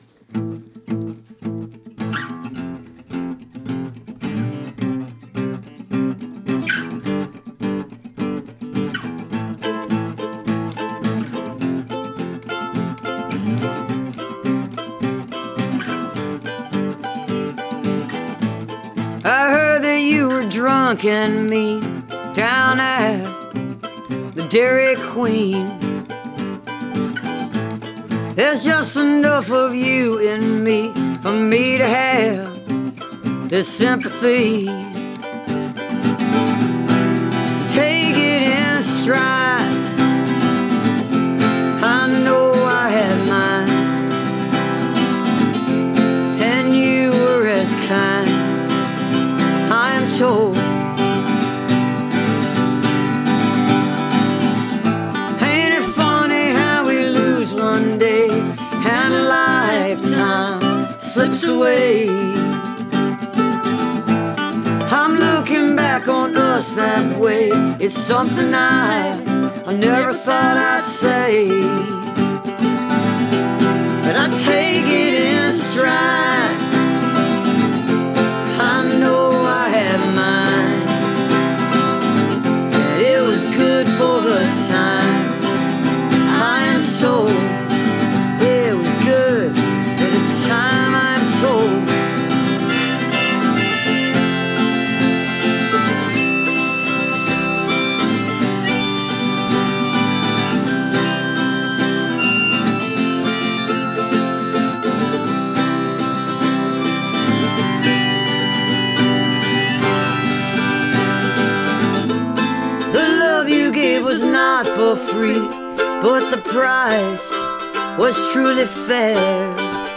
(captured from webcast)